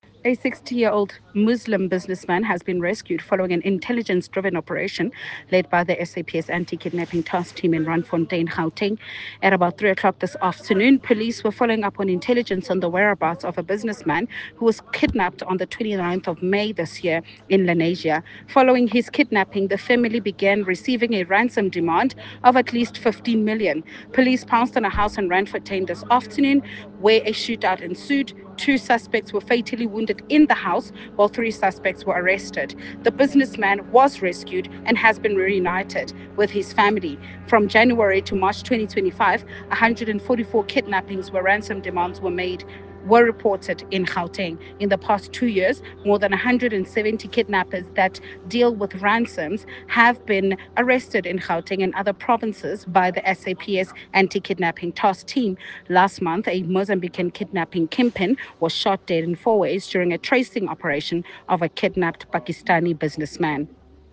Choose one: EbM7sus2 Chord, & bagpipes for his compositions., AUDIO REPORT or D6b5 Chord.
AUDIO REPORT